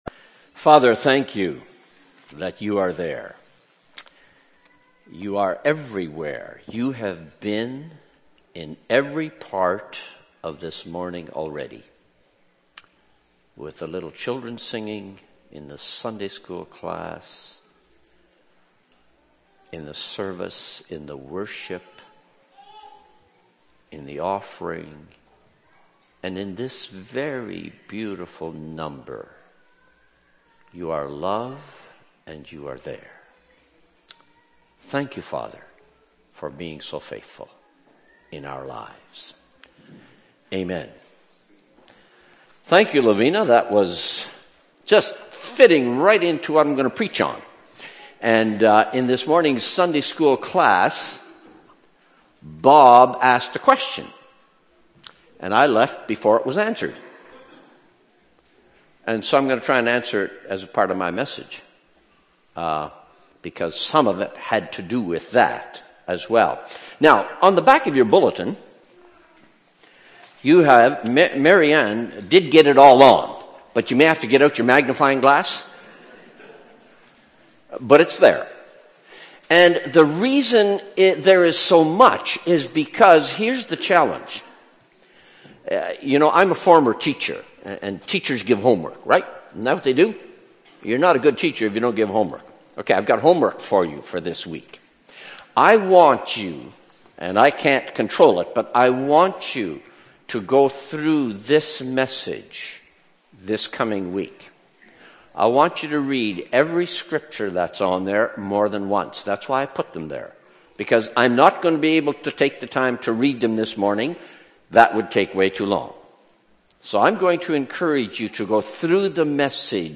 Feb. 5, 2012 – Sermon